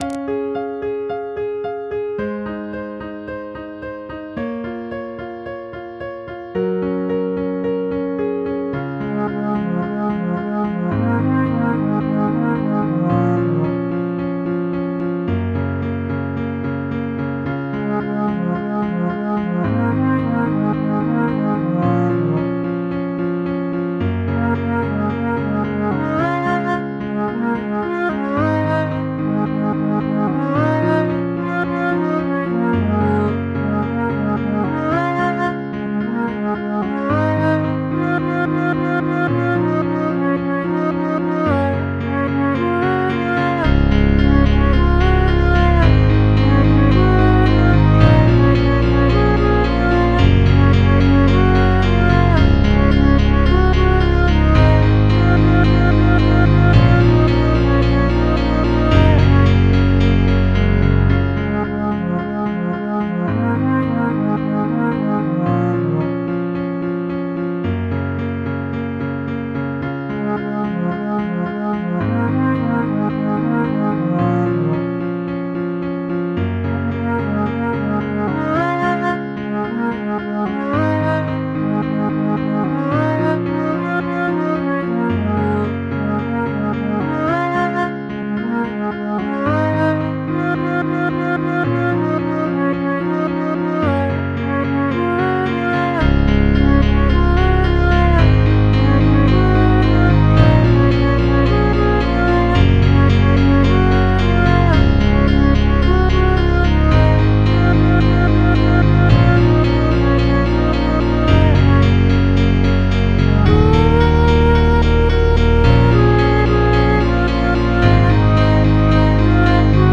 MIDI 24.72 KB MP3
Made using FL Studio